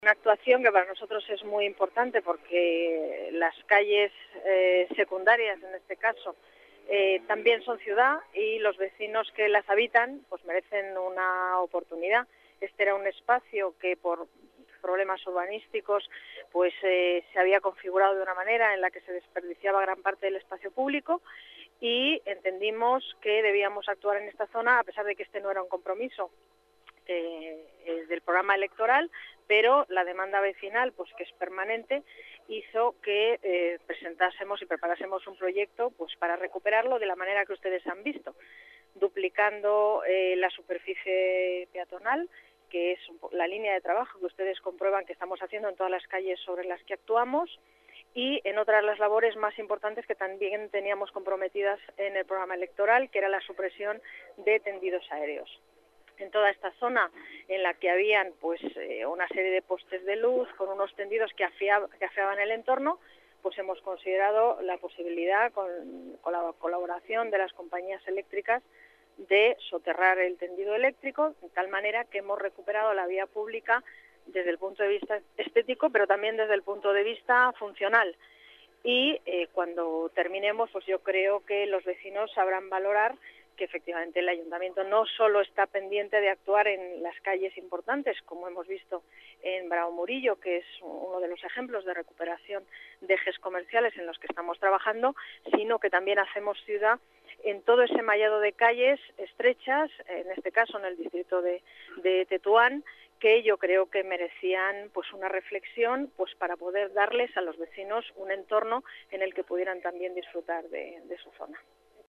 Nueva ventana:Declaraciones de la delegada de Obras y Espacios Públicos, Paz González